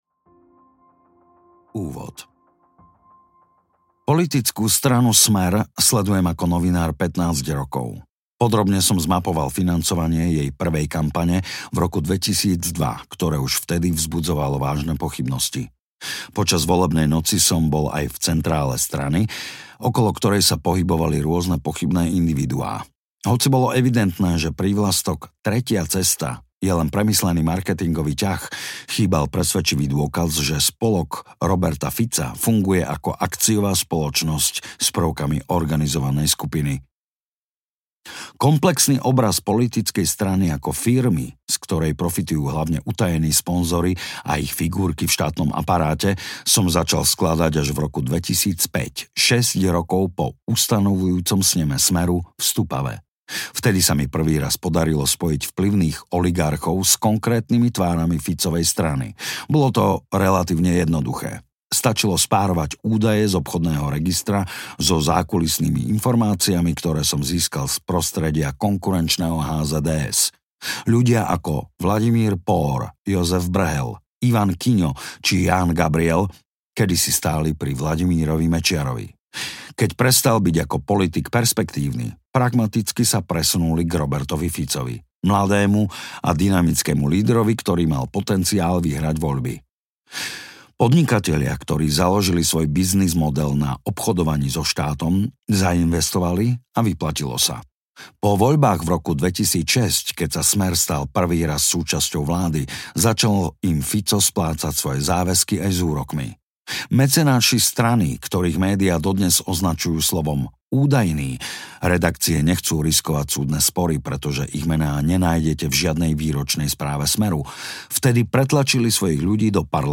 Vlastnou hlavou audiokniha
Ukázka z knihy